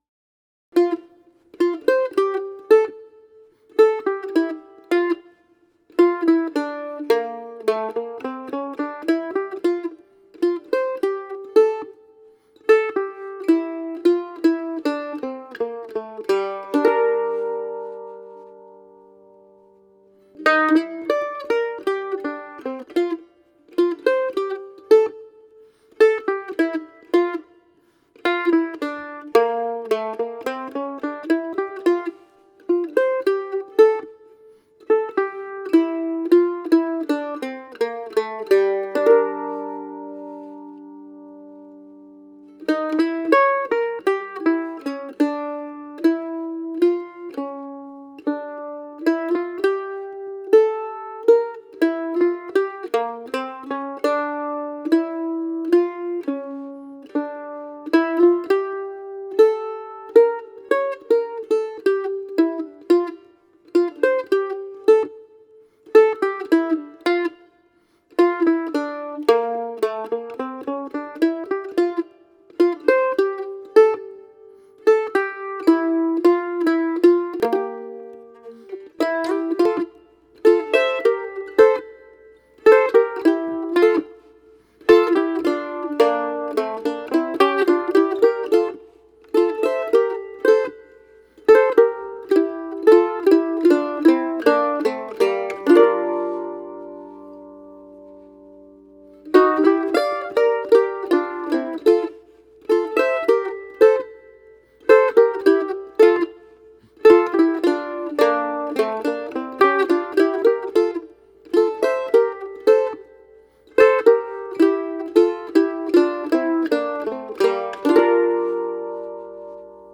mandolin